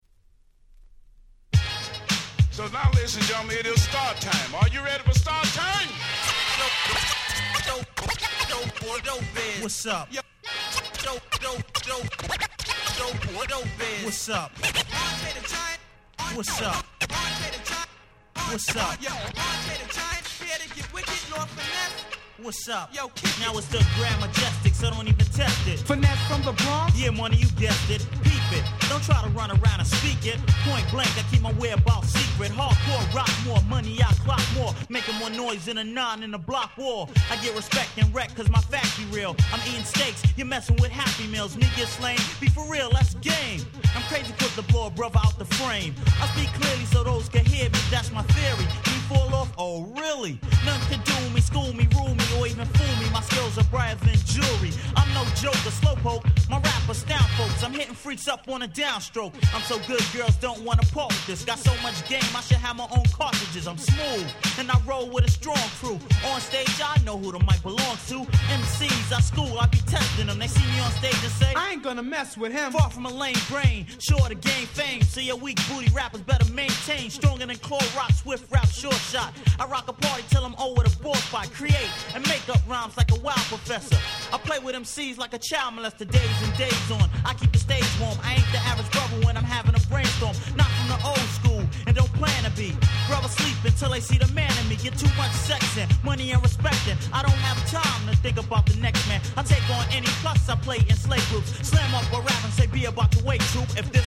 Boom Bap ブーンバップ